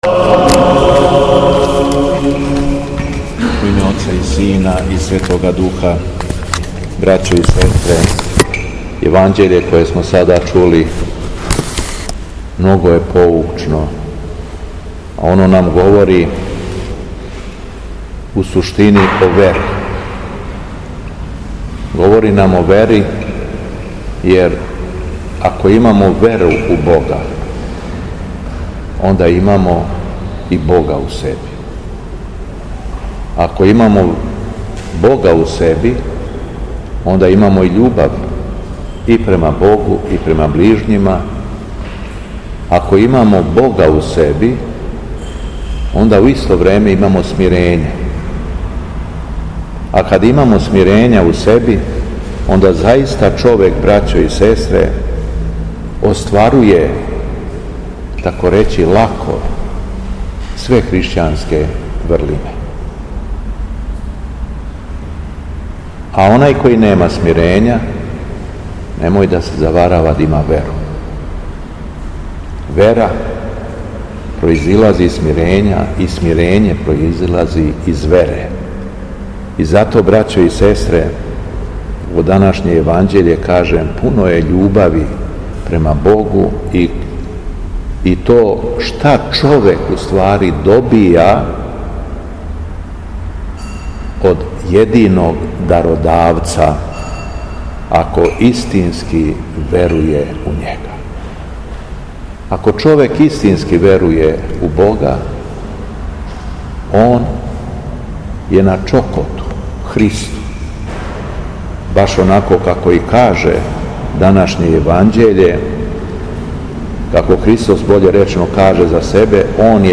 Беседа Његовог Високопреосвештенства Митрополита шумадијског г. Јована
У понедељак петнаести по Духовима, када наша света Црква прославља светог мученика Маманта, Његово Високопреосвештенство Митрополит шумадијски Господин Јован, служио је свету архијерејску литургију у храму Светога Саве, у крагујевачком насељу Аеродром.